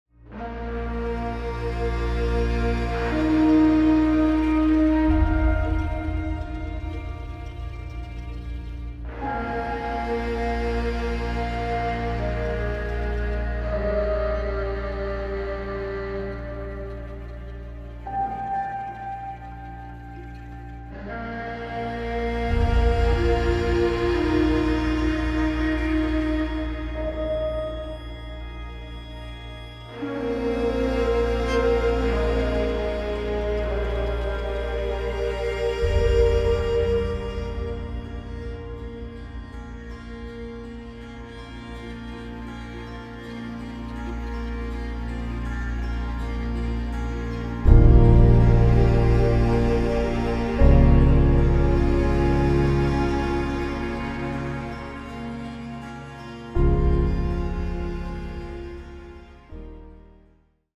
Dystopian Atmosphere